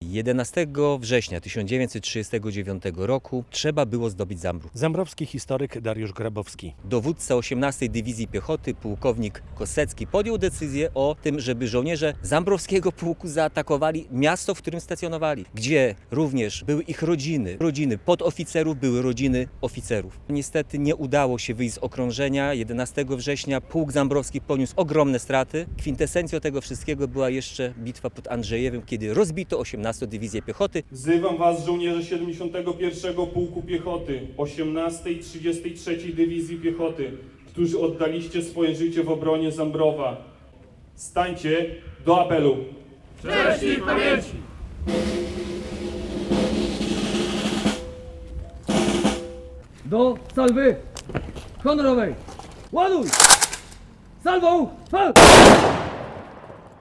84. rocznica bitwy o Zambrów - rozpoczęły się oficjalne obchody - relacja